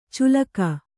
♪ culaka